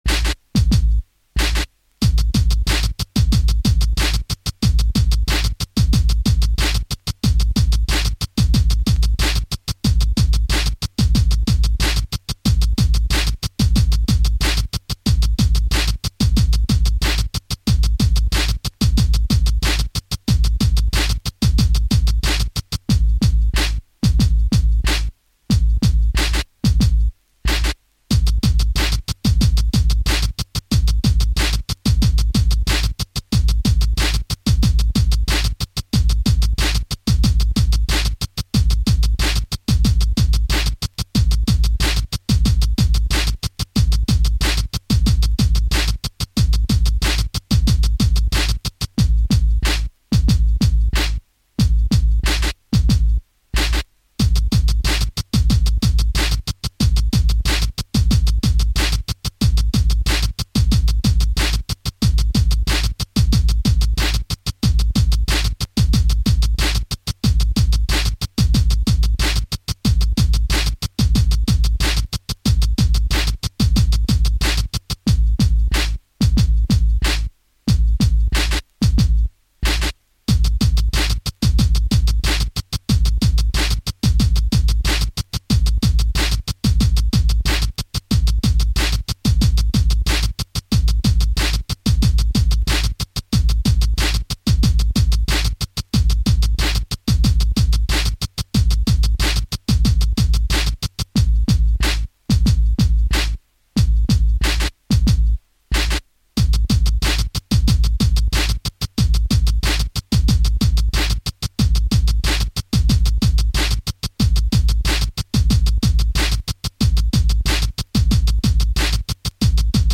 Genre: Hip Hop
Style: DJ Battle Tool, Cut-up/DJ, Turntablism